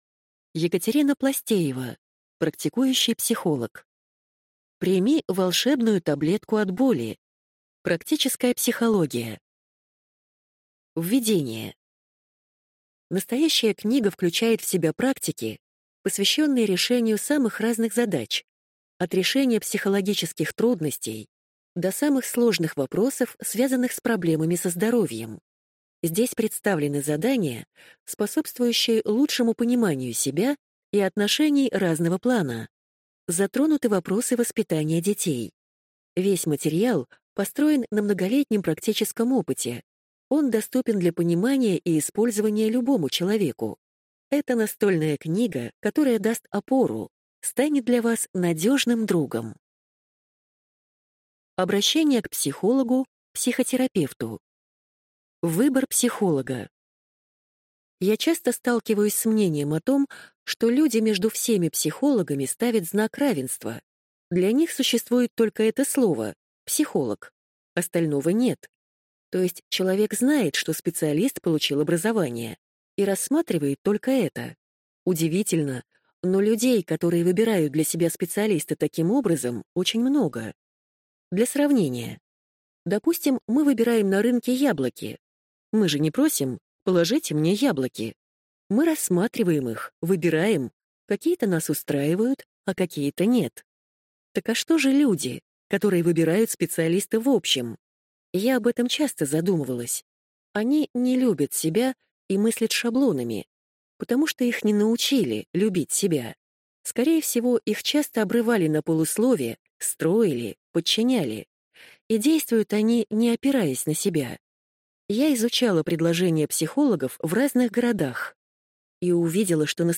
Аудиокнига Прими волшебную таблетку от боли. Практическая психология | Библиотека аудиокниг